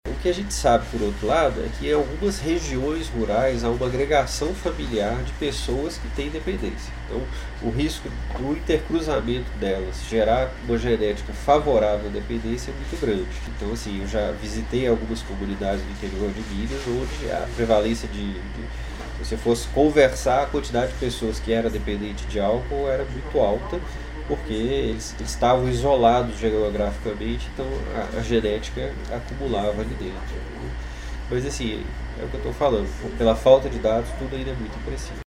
Especialista fala sobre a realidade atual do consumo de drogas lícitas e ilícitas no interior.